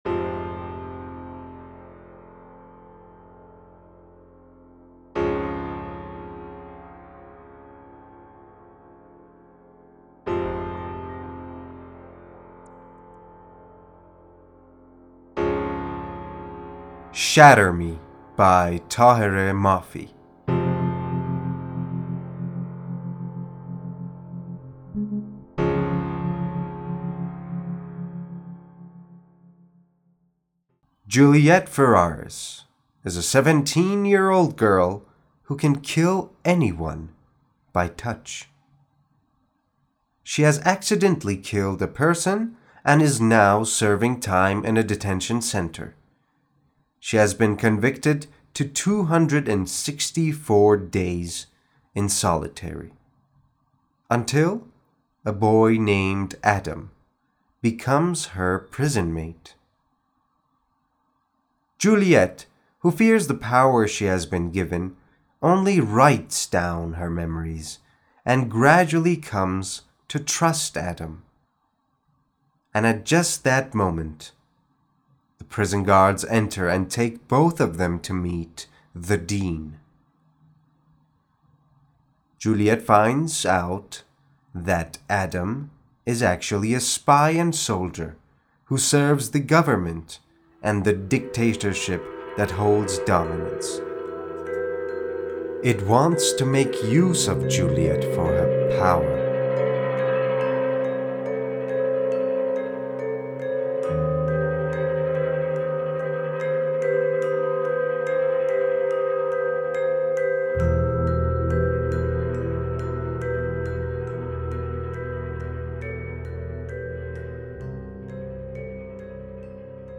معرفی صوتی کتاب Shatter Me